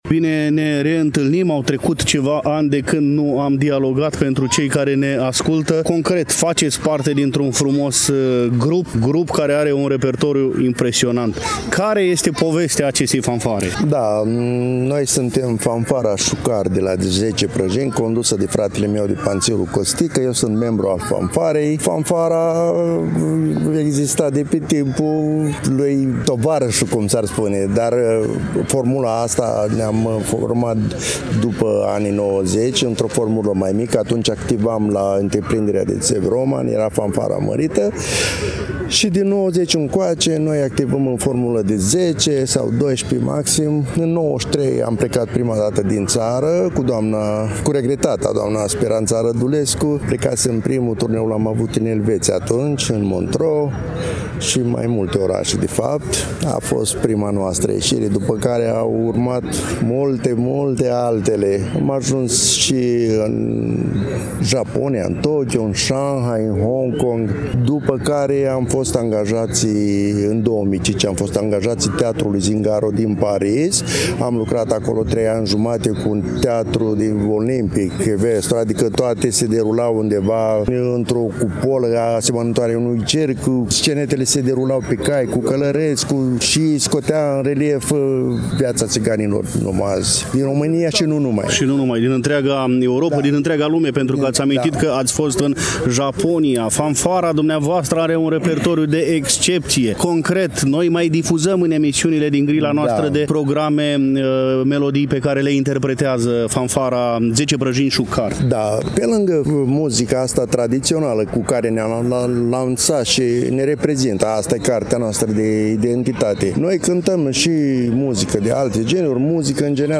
Reamintim că, în ediția de astăzi a emisiunii noastre, relatăm de la „Bazarul Cultural al Romilor”, manifestare care s-a desfășurat sâmbătă, 6 aprilie 2024, începând cu ora 11, în Parcul Copou din târgul Iașilor.